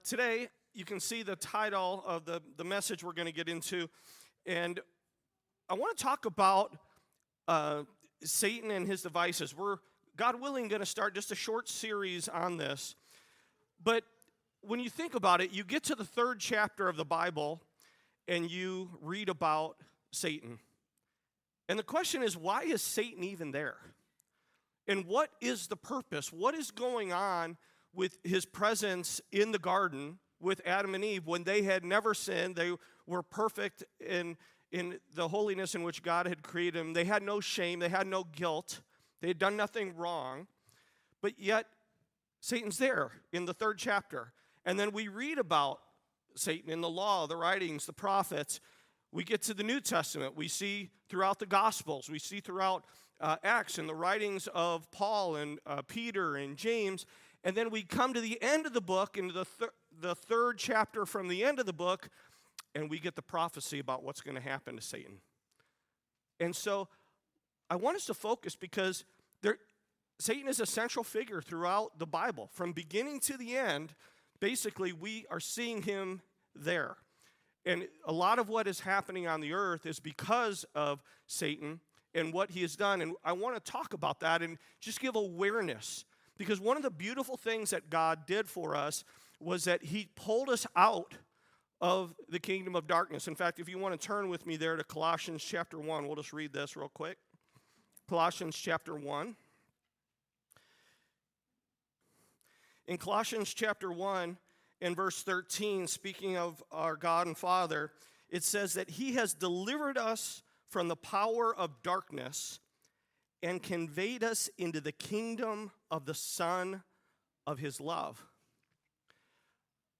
he delivers a powerful message about Satan and his devices.